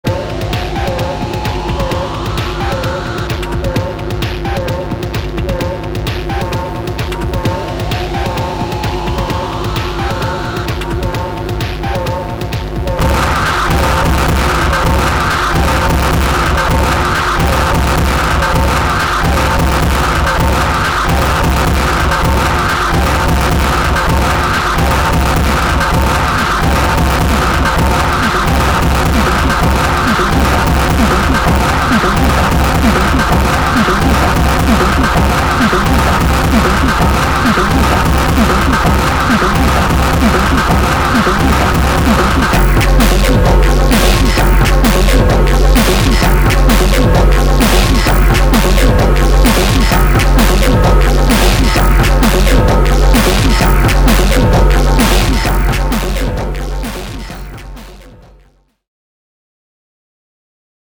electro-industrial